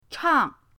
chang4.mp3